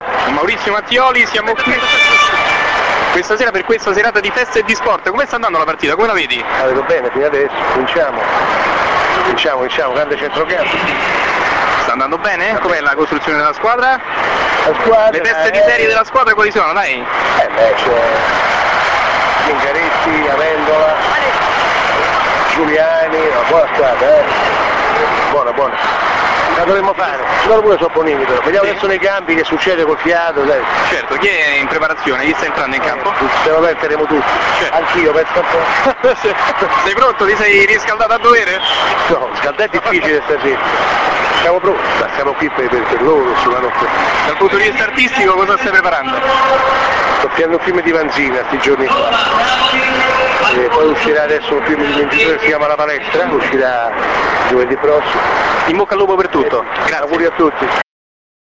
Speciale Derby del Cuore - INTERVISTE AI PERSONAGGI